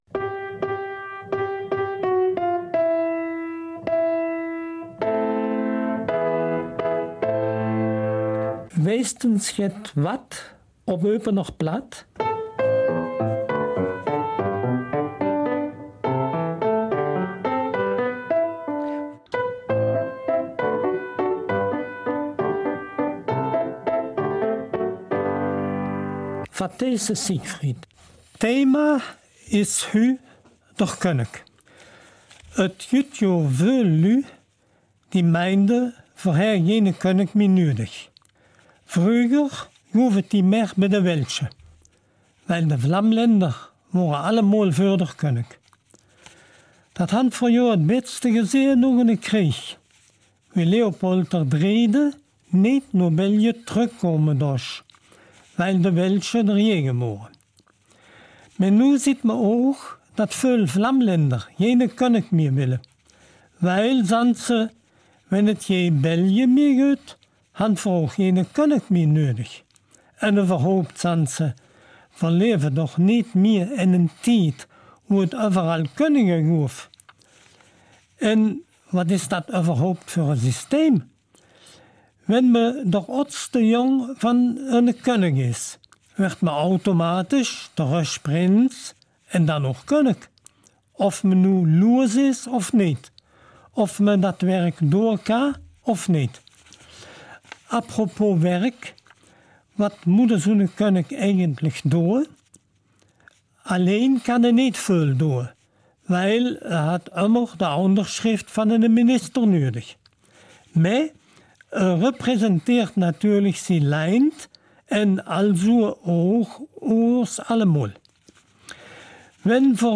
Eupener Mundart - 8. September